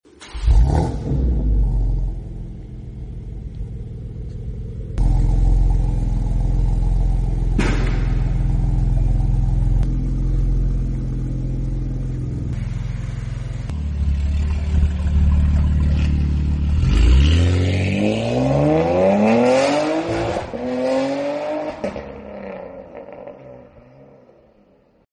BMW G80 M3 Competition equipped with our Rogue Performance Equal Length Valvetronic Turbo-Back Exhaust, engineered to bring out those sexy deep BMW exhaust notes